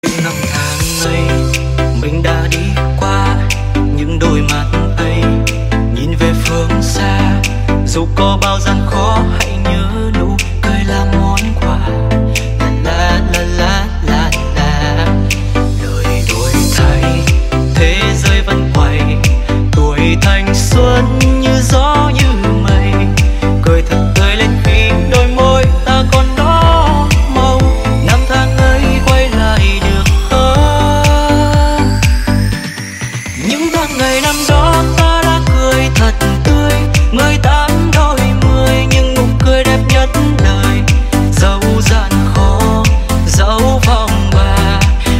Nhạc Chuông TikTok